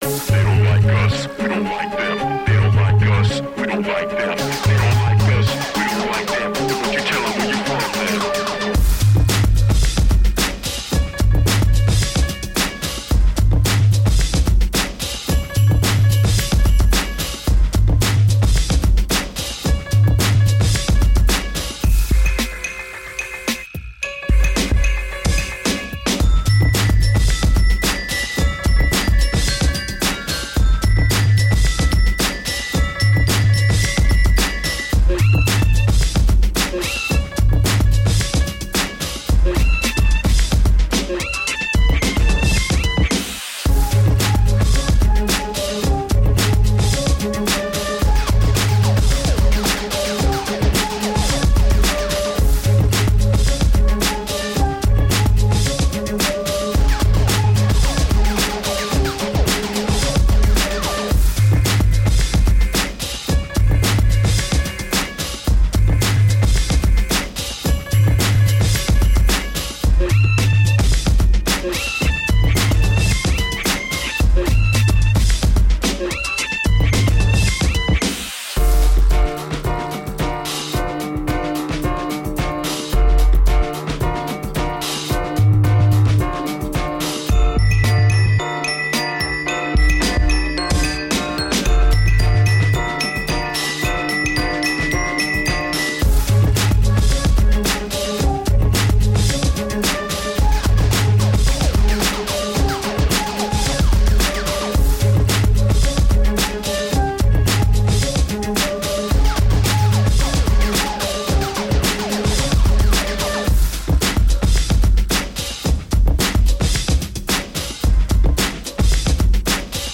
Tagged as: Electronica, Funk